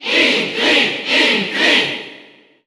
File:Inkling Cheer Italian SSBU.ogg
Category: Crowd cheers (SSBU) You cannot overwrite this file.
Inkling_Cheer_Italian_SSBU.ogg.mp3